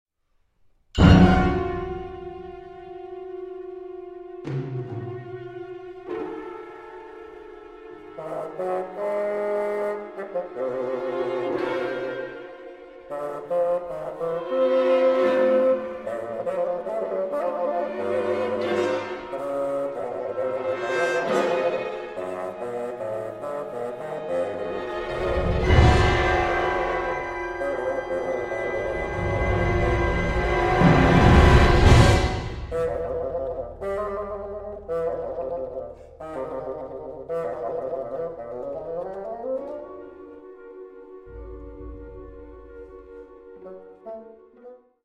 First Recordings made in the Presence of the Composer